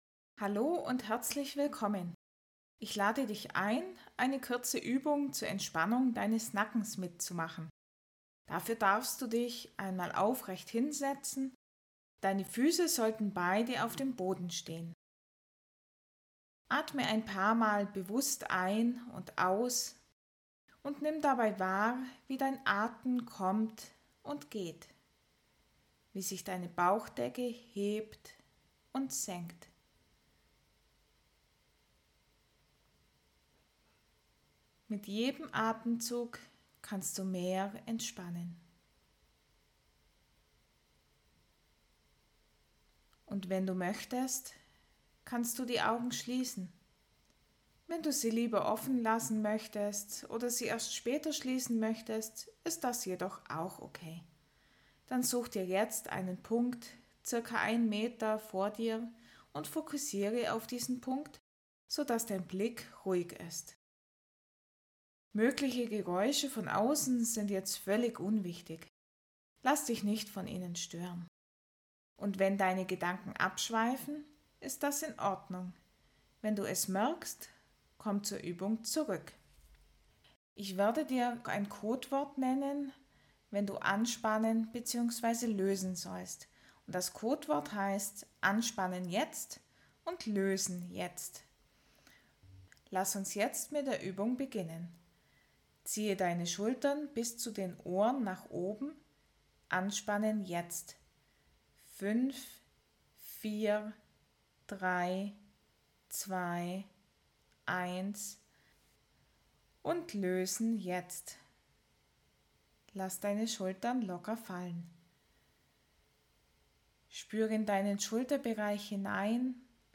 Dann ist diese kurze, geführte Übung genau das
Lass dich durch klare Anleitungen begleiten, erlebe, wie